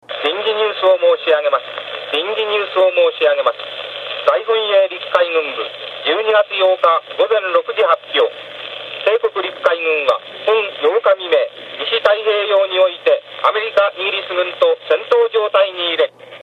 太平洋戦争（大東亜戦争）関連のラジオ放送録音資料
開戦臨時ニュース （１６秒） 　の　試聴mp3　←クリック